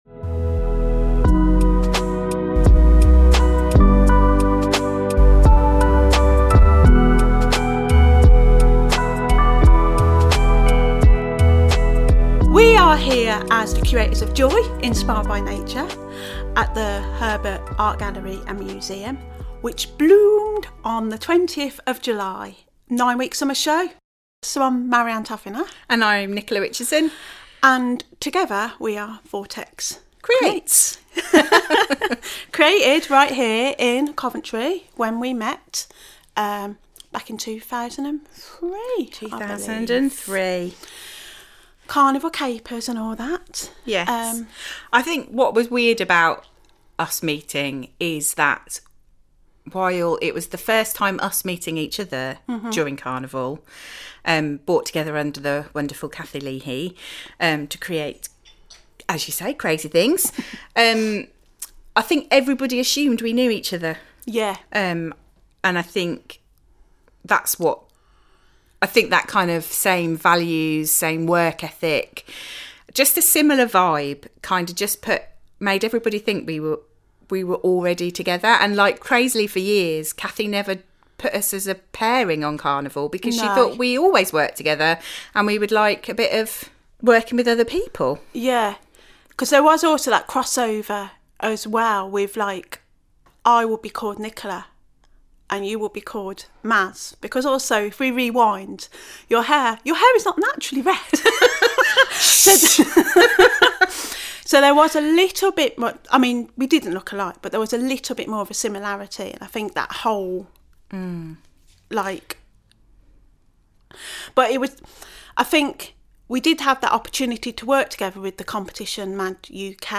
Here Vortex Creates, get together at The Herbert Art Gallery and Museum to talk about their show, Joy…inspired by nature, which many of you will have visited over summer 2024.